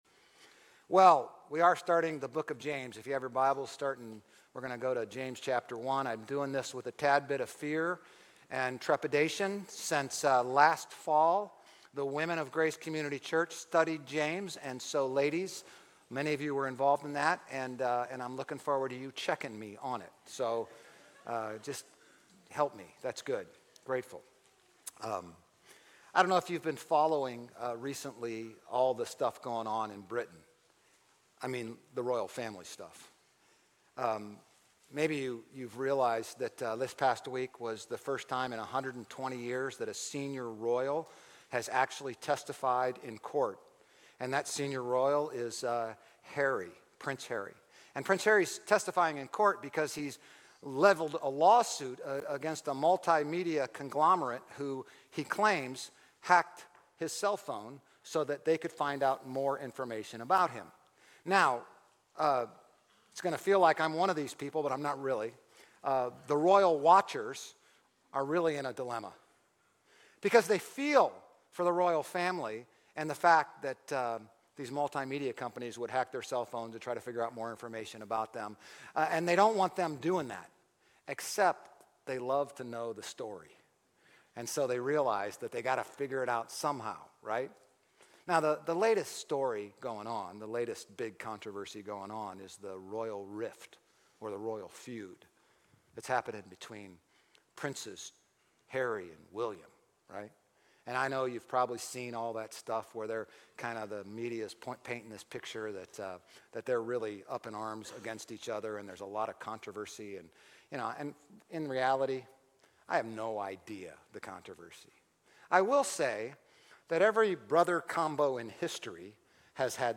GCC-OJ-June-11-Sermon.mp3